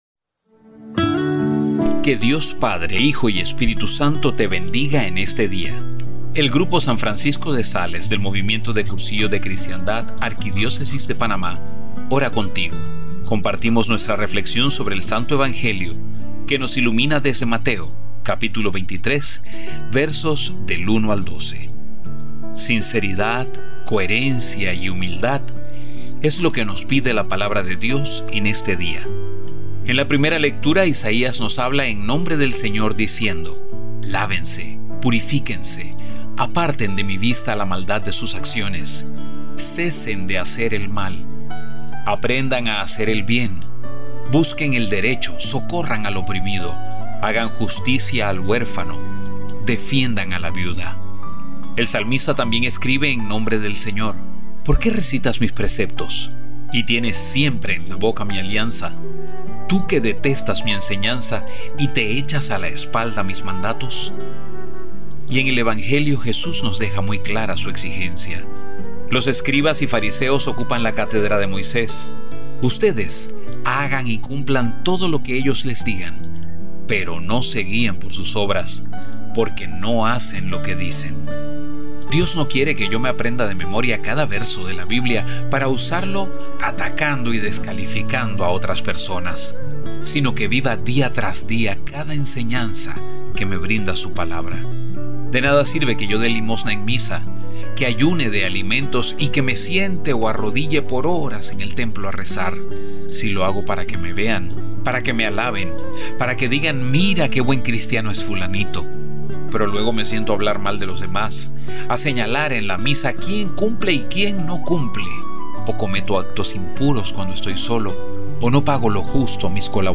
audioreflexión